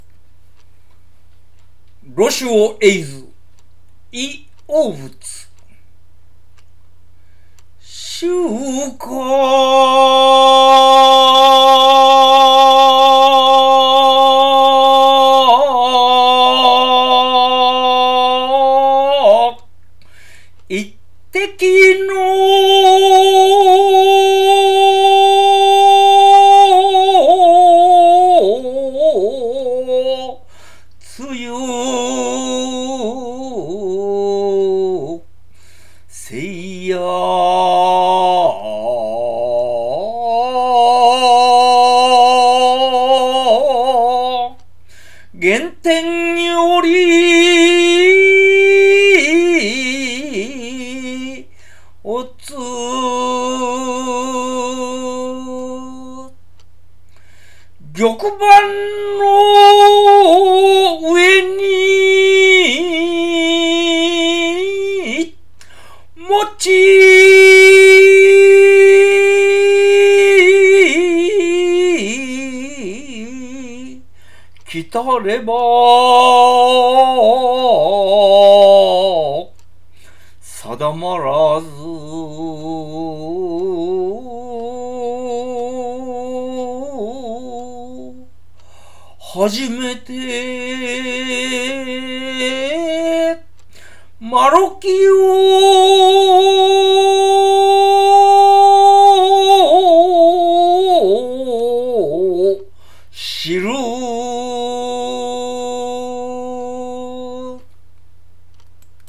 この詩「露珠を詠ず」には秋の夜の静けさと神秘さが良く表現されている。 露珠を詠ず譜面 静かに、情景を味わい詠じてみたい。